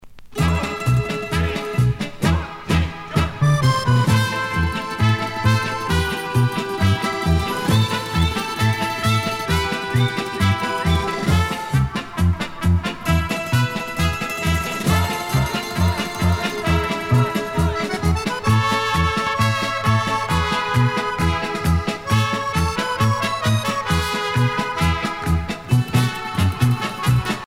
danse : kazatchok
Pièce musicale éditée